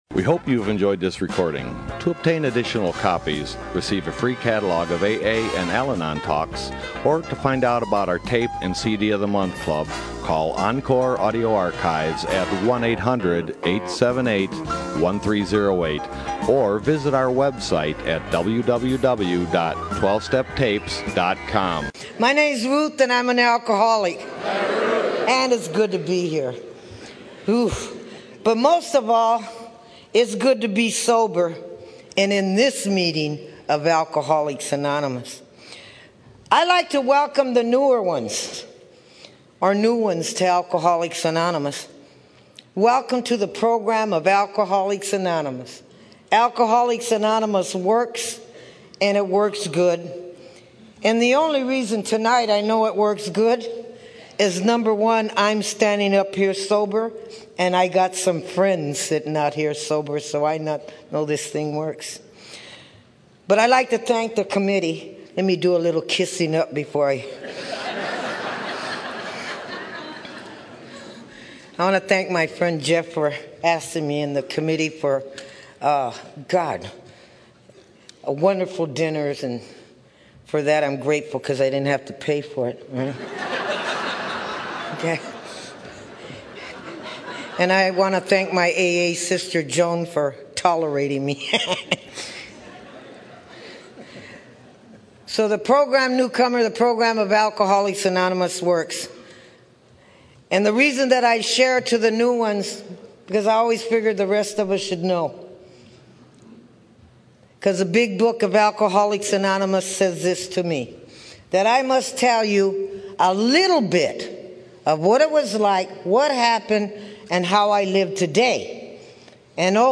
San Diego Spring Roundup 2007